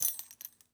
foley_keys_belt_metal_jingle_16.wav